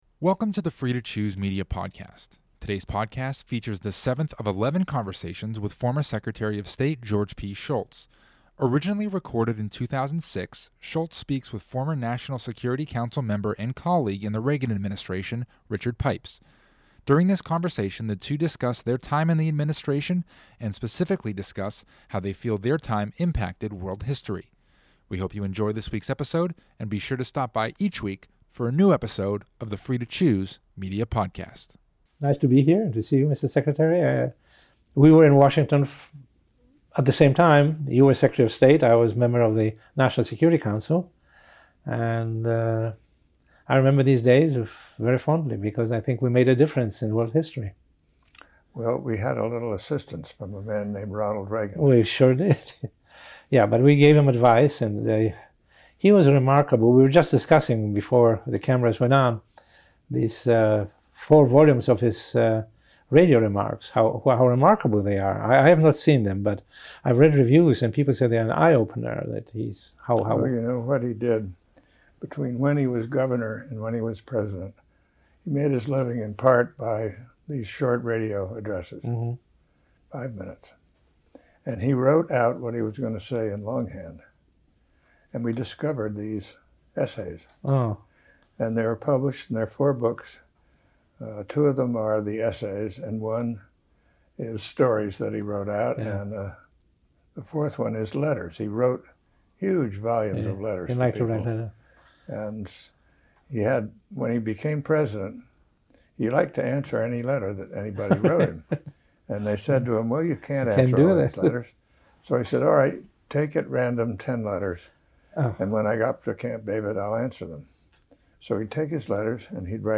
Originally recorded in 2006, Shultz speaks with a former National Security Council member and colleague in the Reagan administration, Richard Pipes. During the conversation, the two talk about their time in the administration and specifically discuss how they feel their time impacted world history.